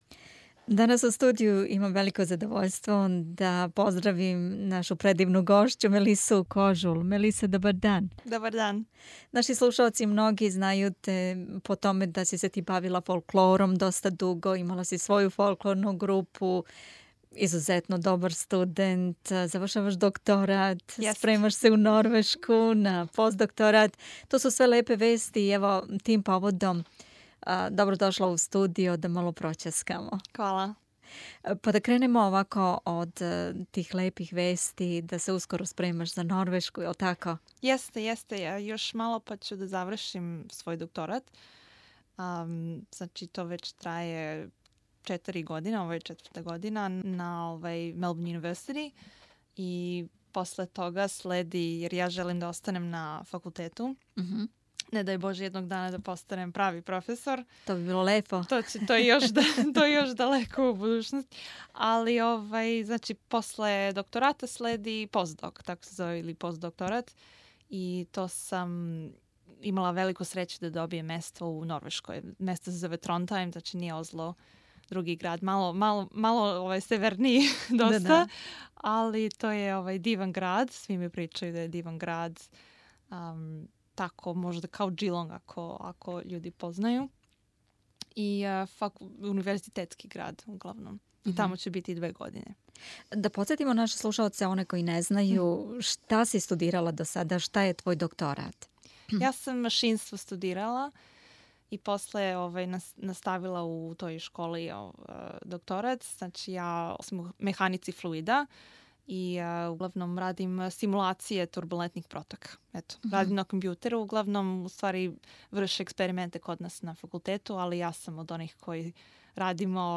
Пред сам полазака посетила је наш студио у Мелбурну и разговарали смо о свему томе....